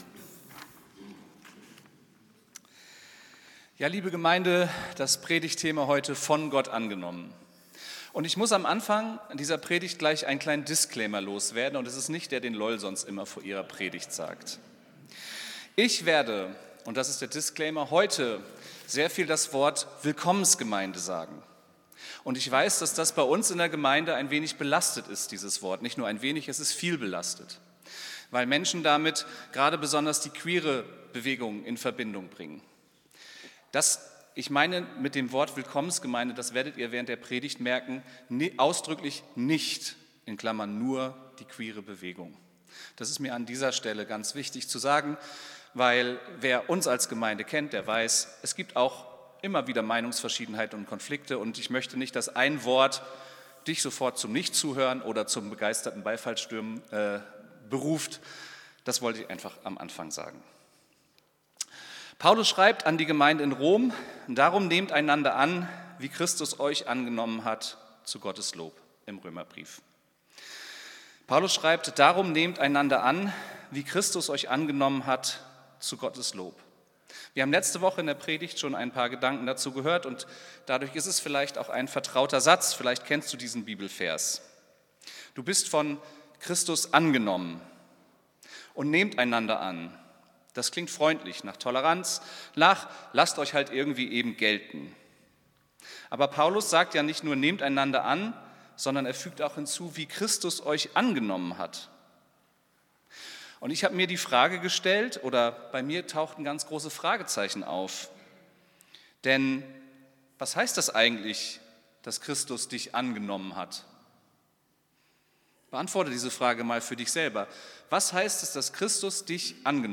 Predigt vom 21.12.2025